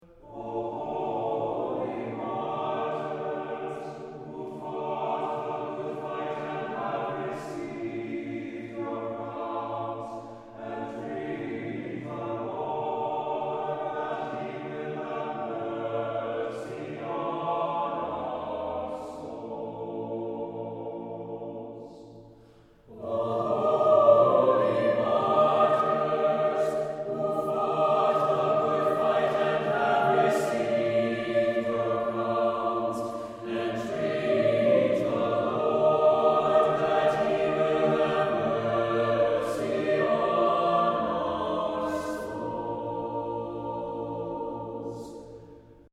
Common Russian Chant